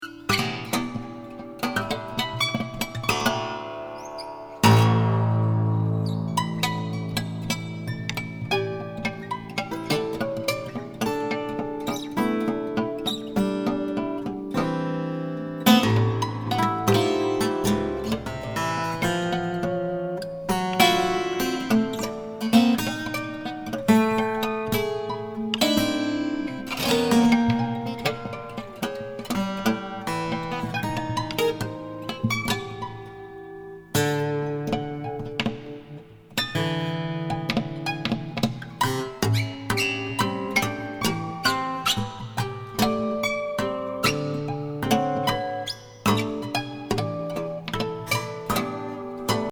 鬼才ギタリスト・デュエット95年作。芳醇ミニマム・サウンド。